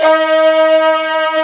Index of /90_sSampleCDs/Roland LCDP13 String Sections/STR_Combos 2/CMB_StringOrch 1
STR VIOLI08R.wav